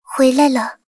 追猎者战斗返回语音.OGG